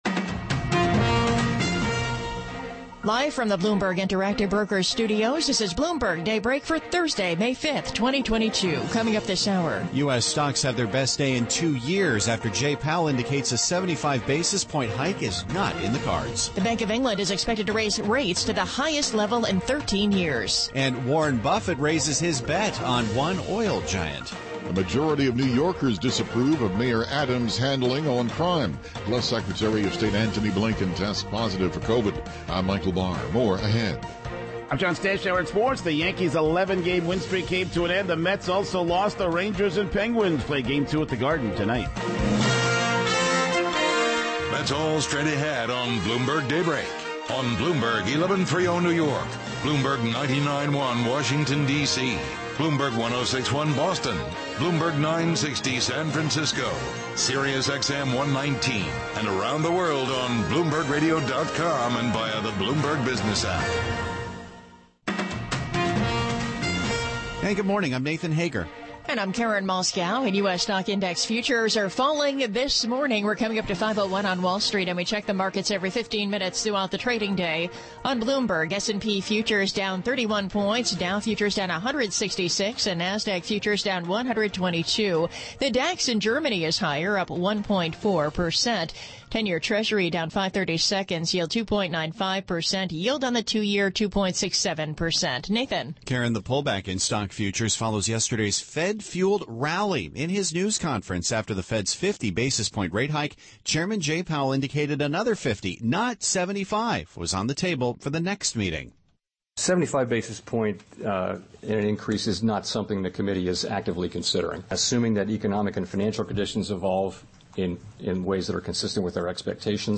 Bloomberg Daybreak: May 5, 2022 - Hour 1 (Radio) - Transcript and Chapters - from Podcast Bloomberg Daybreak: US Edition